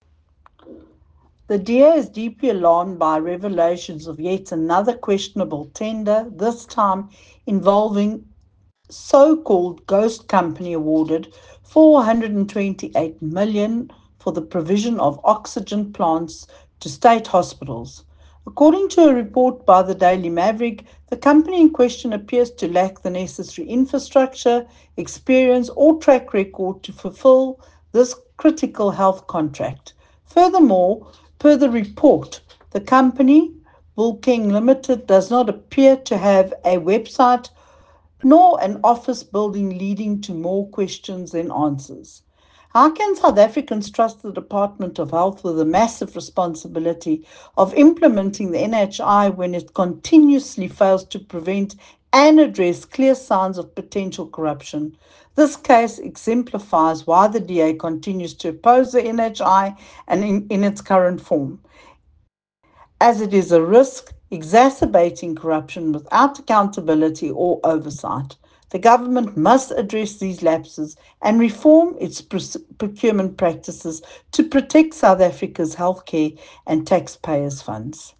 soundbite by Michele Clarke MP.